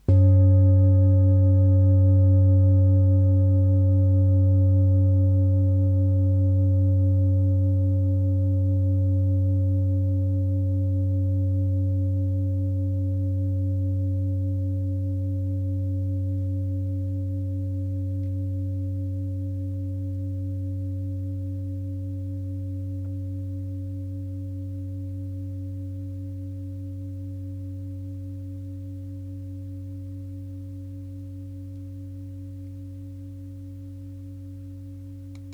Klangschale TIBET Nr.25
Sie ist neu und ist gezielt nach altem 7-Metalle-Rezept in Handarbeit gezogen und gehämmert worden.
(Ermittelt mit dem Filzklöppel oder Gummikernschlegel)
Die Solfeggio-Frequenz Liebe
klangschale-tibet-25.wav